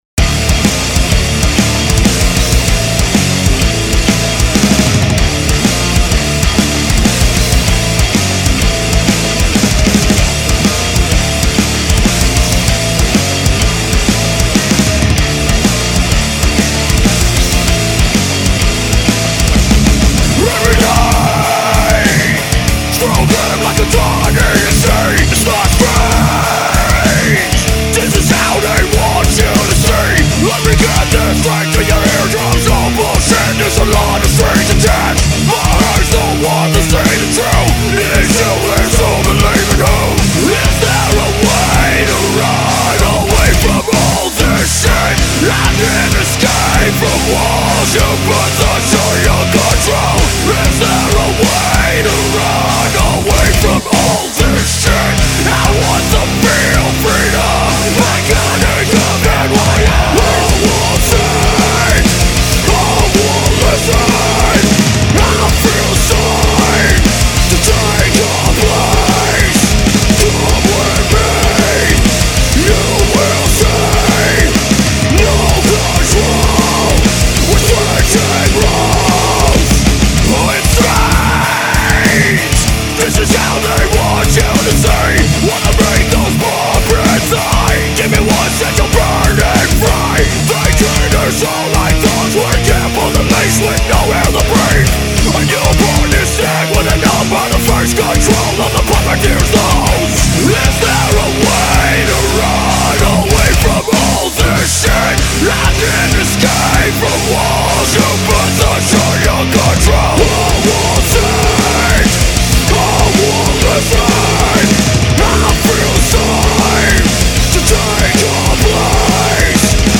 VIO-LENT.